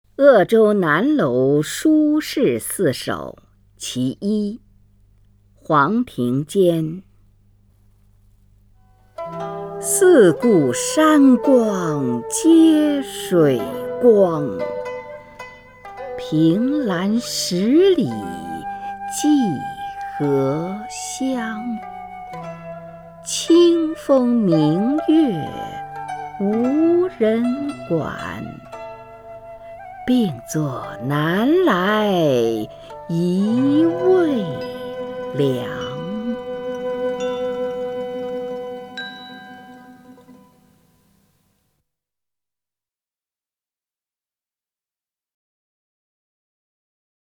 首页 视听 名家朗诵欣赏 虹云
虹云朗诵：《鄂州南楼书事四首·其一》(（北宋）黄庭坚)